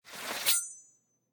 draw1.ogg